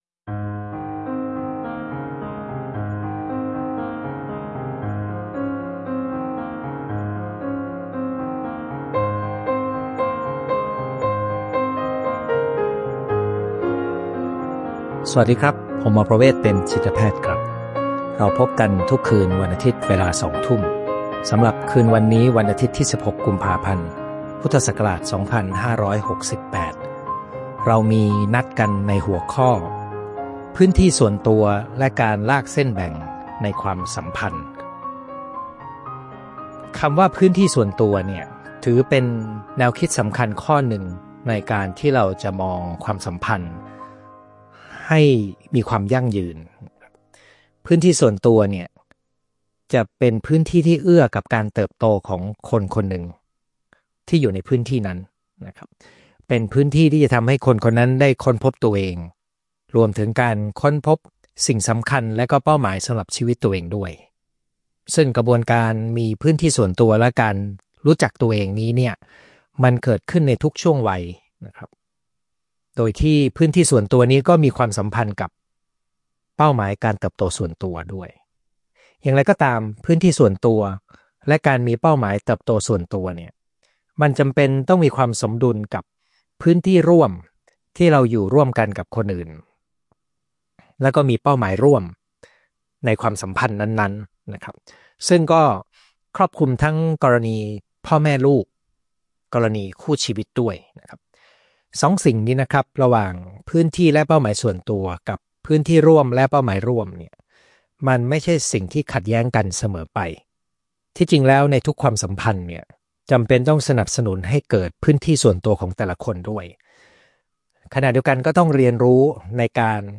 ไลฟ์วันอาทิตย์ที่ 16 กุมภาพันธ์ 2568 เวลาสองทุ่ม